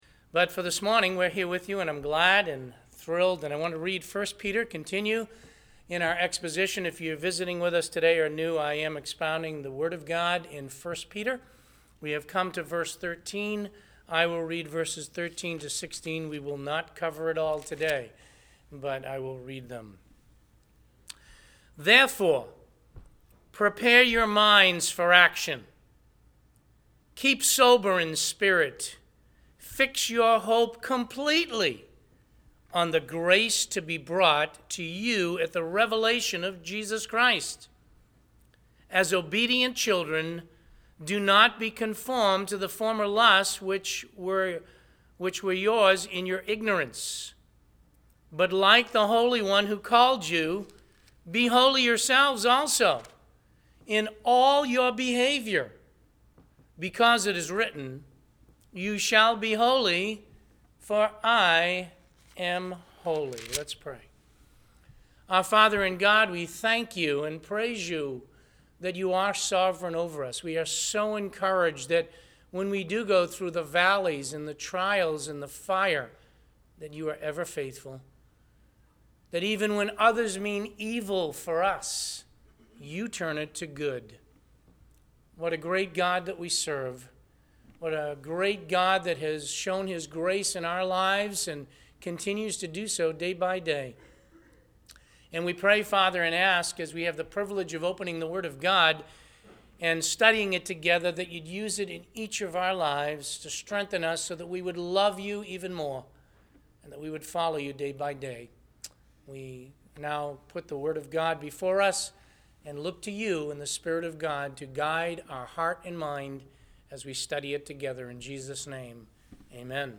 Listen to the sermon “A Call for Action.”